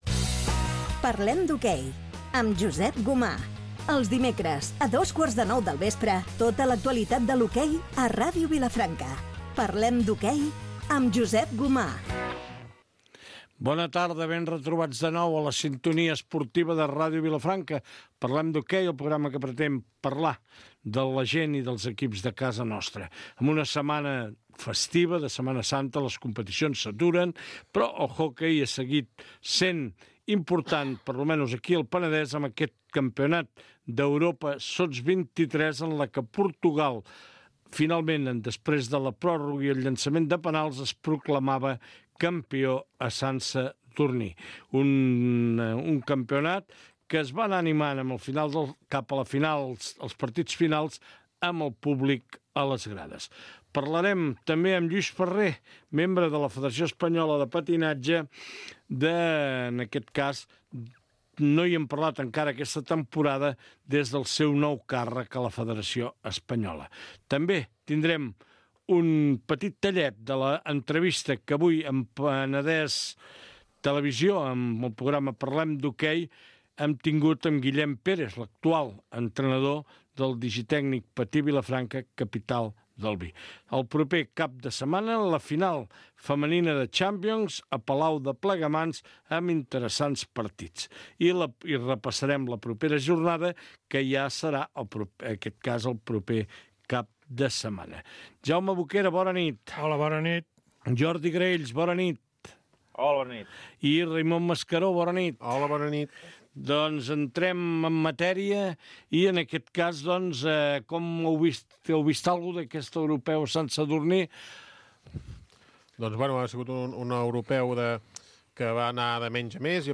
Especial final de temporada des del Casino